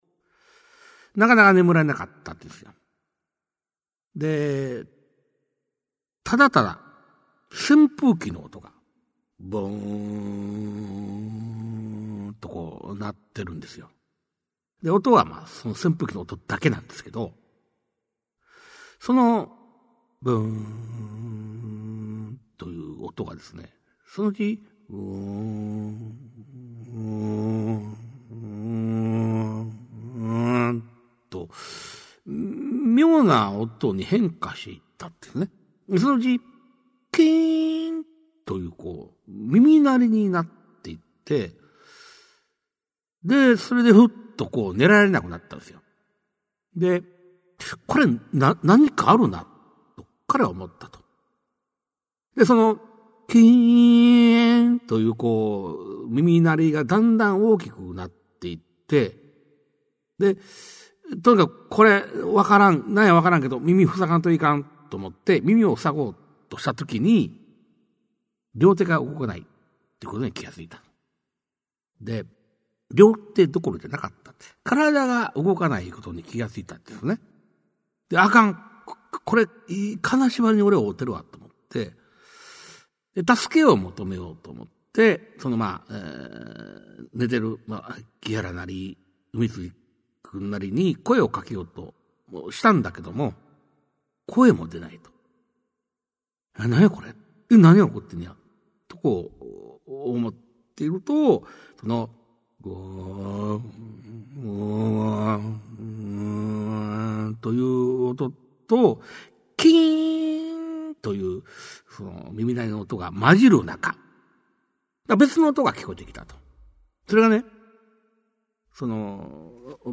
[オーディオブックCD] 市朗怪全集 五十三